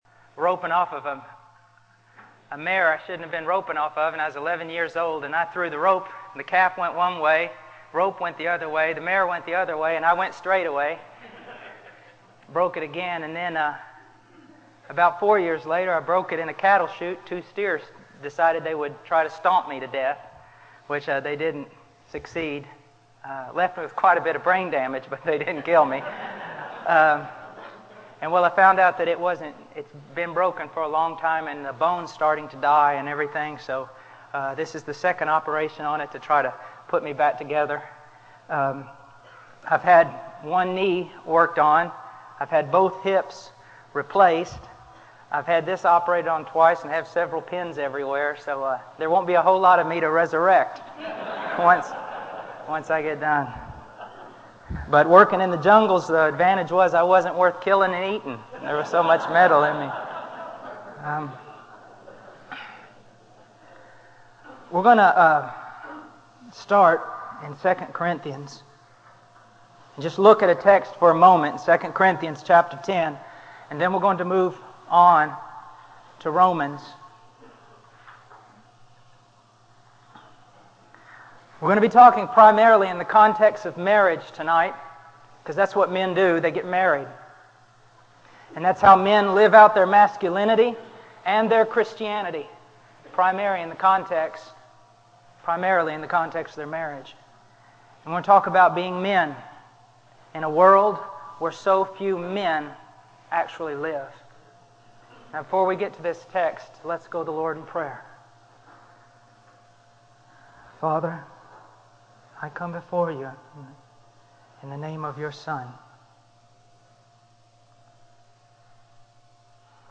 In this sermon, the preacher emphasizes the importance of bringing every aspect of our lives under the lordship of Christ. He highlights the need to bring our thought life, eyes, ears, heart, hands, and feet into subjection to the law of Christ.